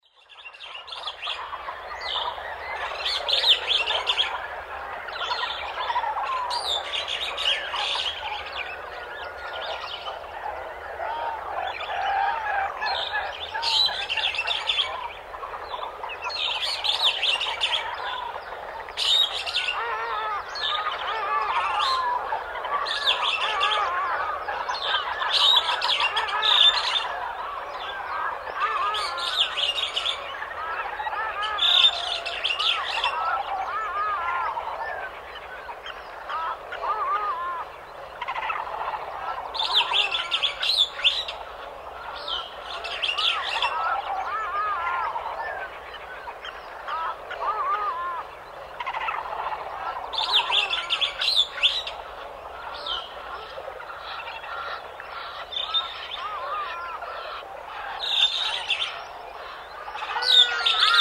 Звуки леса
Африканские джунгли пробуждаются утренними трелями птиц